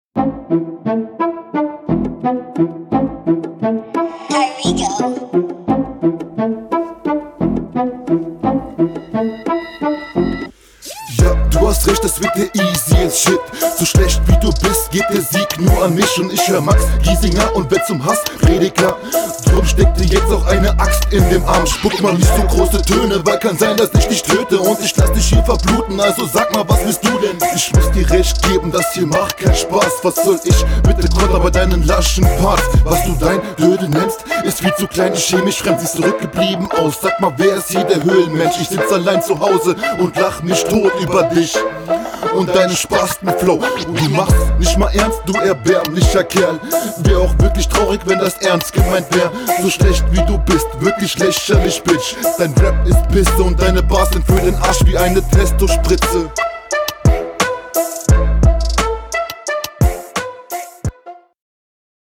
Guter Stimmeinsatz, wobei das noch besser klingen würde wenn du mehr am abmischen arbeiten würdest. …
Ohje, was ist das für eine Abmische?